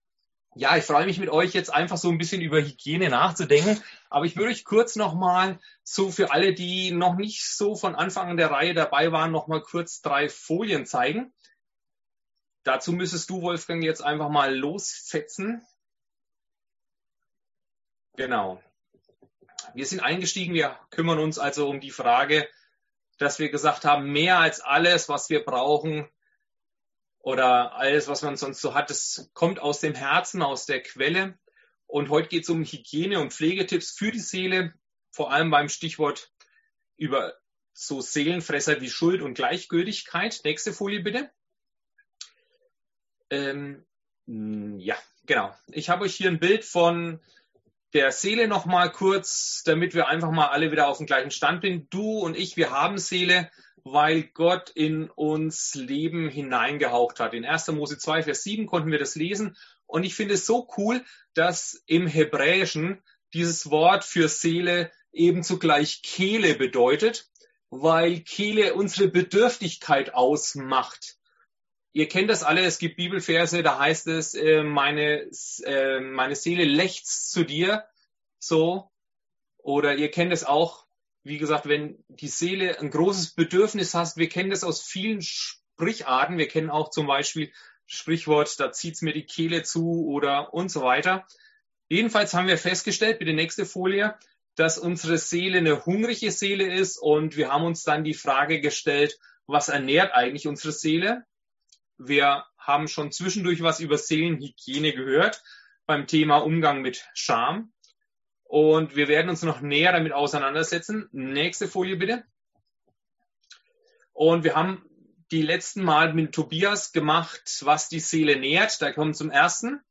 Predigt vom 16. Mai 2021 per Zoom